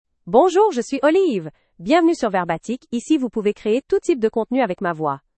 OliveFemale French AI voice
Olive is a female AI voice for French (Canada).
Voice sample
Listen to Olive's female French voice.
Female
Olive delivers clear pronunciation with authentic Canada French intonation, making your content sound professionally produced.